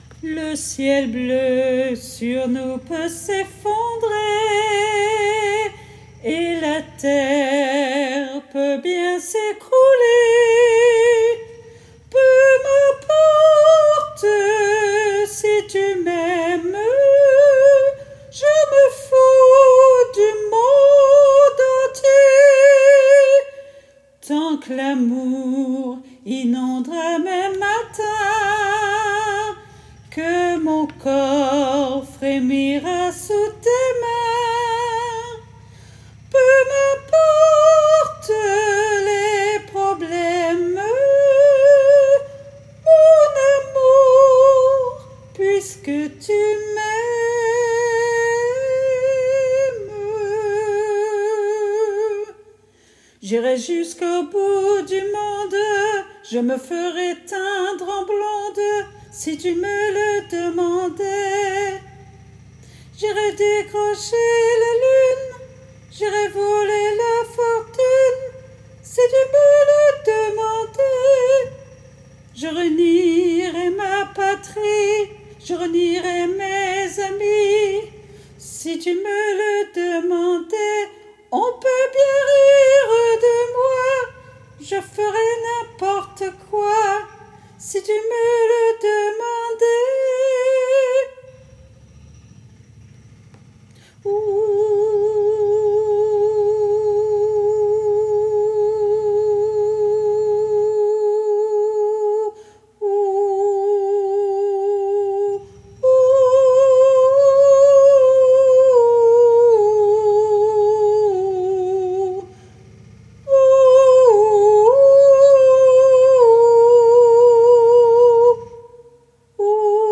MP3 versions chantées
Soprano